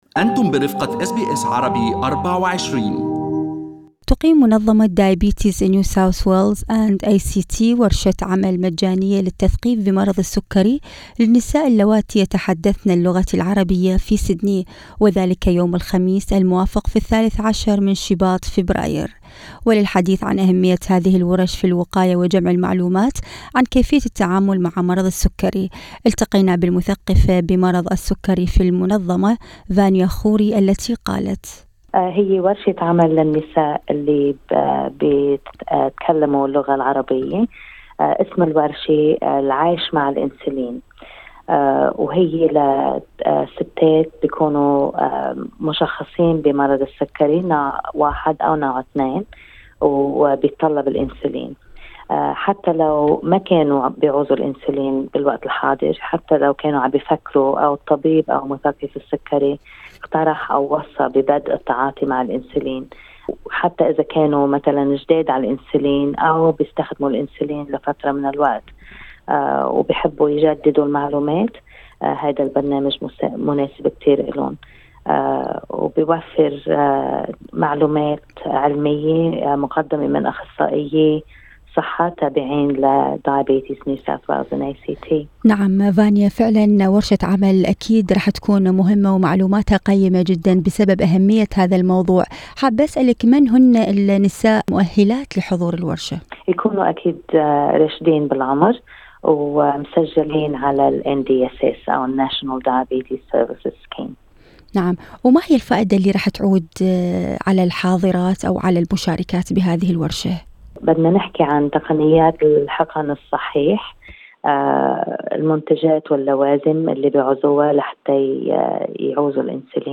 أس بي أس عربي